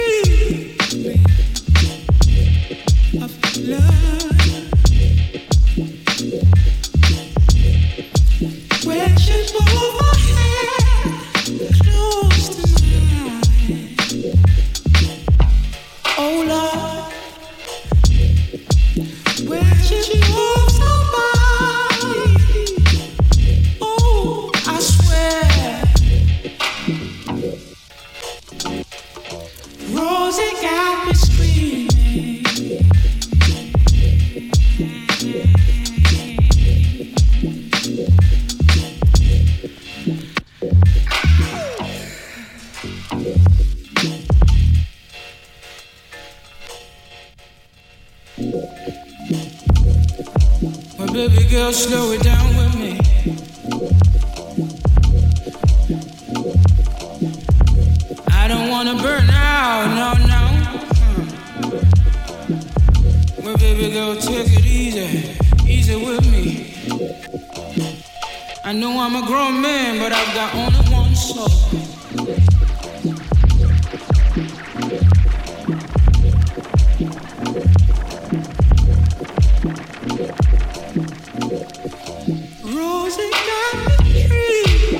falsetto vocals
Electronix House Indie